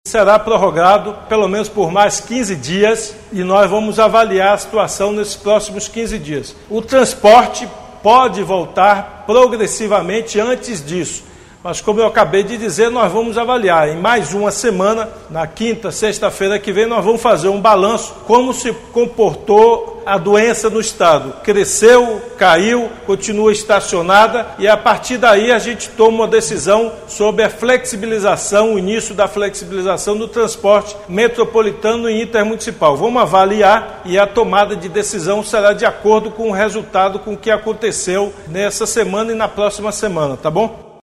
Ouça a sonora de Rui Costa: